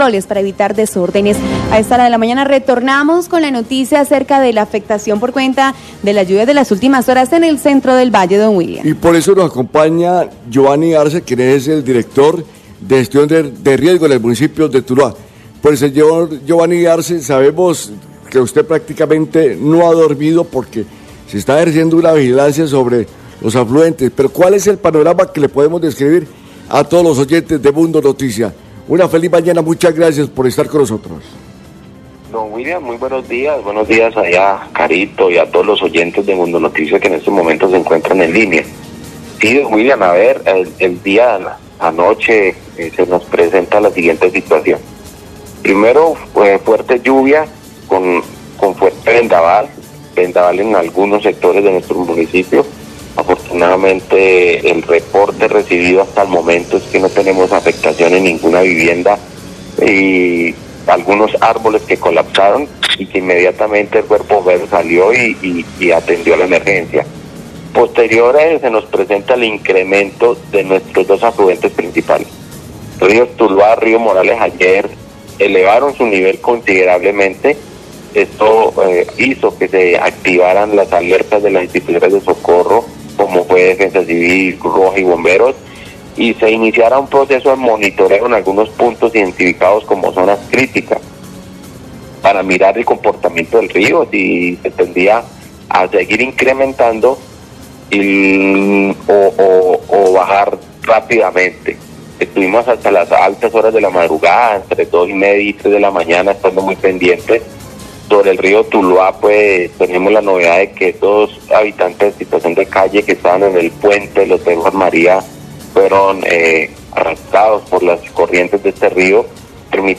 Radio
MUNDO NOTICIAS-TULUÁ